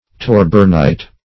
Search Result for " torbernite" : The Collaborative International Dictionary of English v.0.48: Torbernite \Tor"bern*ite\, n. [So named after Torber Bergmann, a Swedish chemist.]